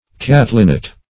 Search Result for " catlinite" : The Collaborative International Dictionary of English v.0.48: Catlinite \Cat"lin*ite\, n. [From George Catlin, an American traveler.] A red clay from the Upper Missouri region, used by the Indians for their pipes.